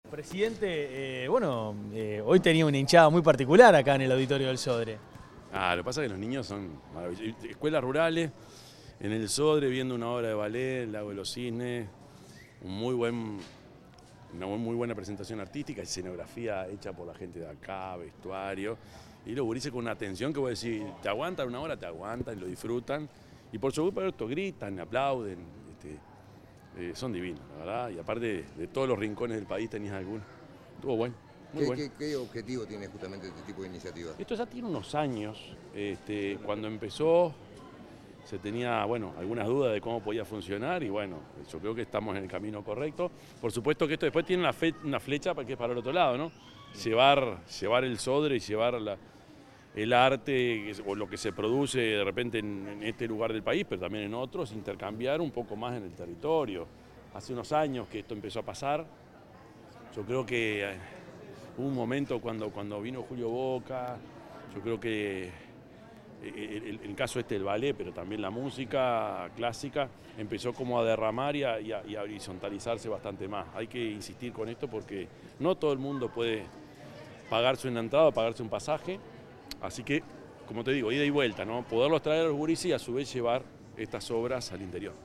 Declaraciones del presidente de la República, Yamandú Orsi
Declaraciones del presidente de la República, Yamandú Orsi 21/05/2025 Compartir Facebook X Copiar enlace WhatsApp LinkedIn El presidente de la República, profesor Yamandú Orsi, dialogó con la prensa luego de la actividad En Primera Fila: Escuelas Rurales al Auditorio, del Ministerio de Educación y Cultura y la Administración Nacional de Educación Pública, realizada en el Auditorio Nacional del Sodre.